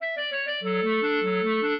clarinet
minuet11-8.wav